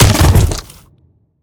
biter-death-big-2.ogg